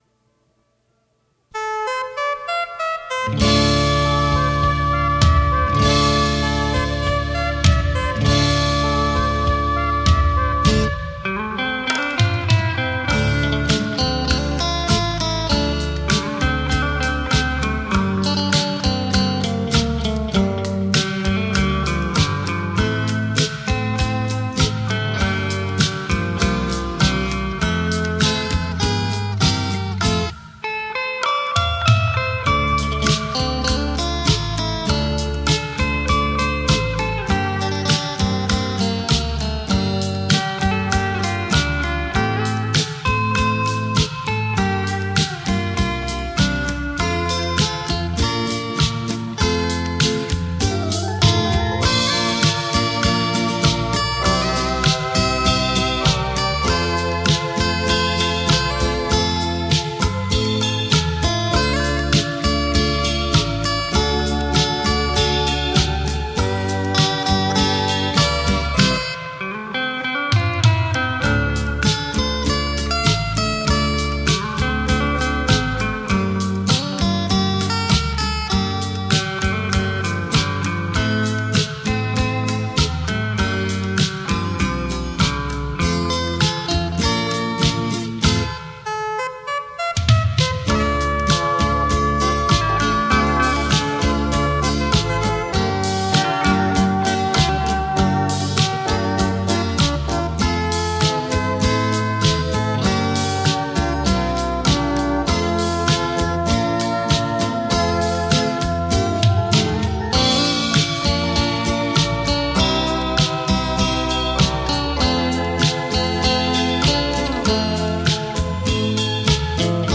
20首经典闽南名曲的吉他演奏，款款柔情浪漫的旋律，